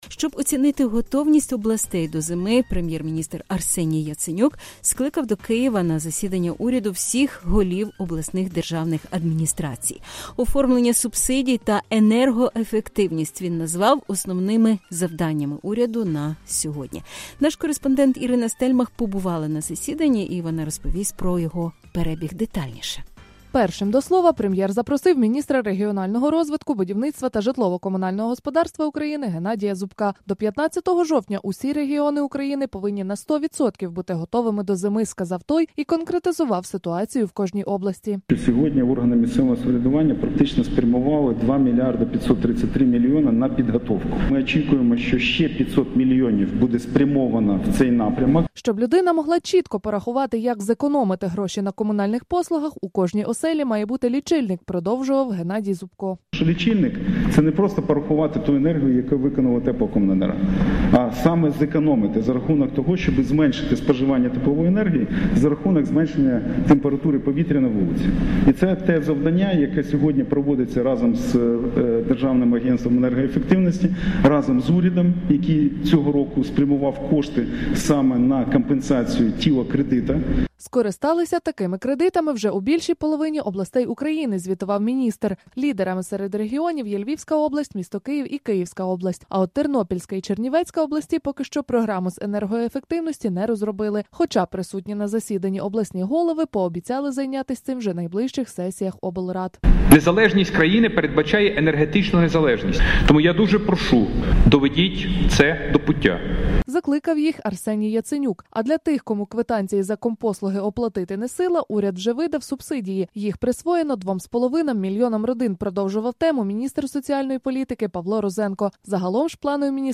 Засідання уряду України, 26 серпня 2015 року